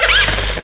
sound / direct_sound_samples / cries / blitzle.aif
-Replaced the Gen. 1 to 3 cries with BW2 rips.